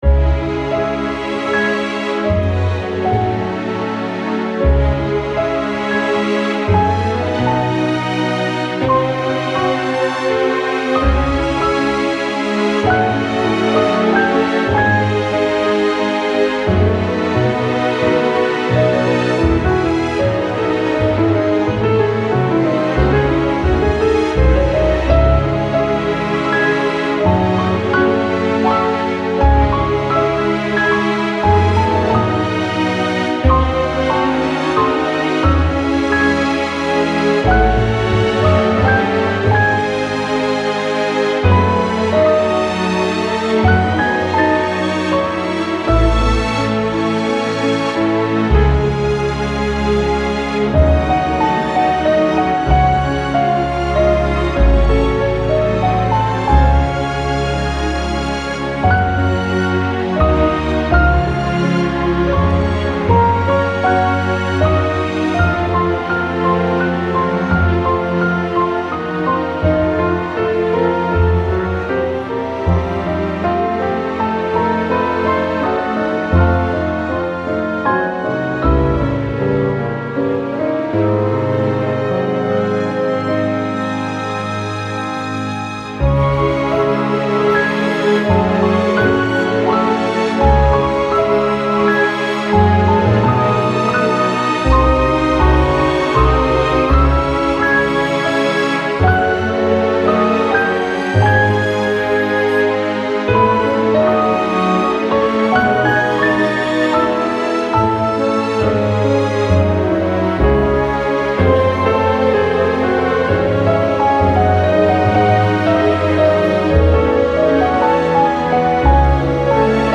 valse - romantique - amoureux - classique - melodique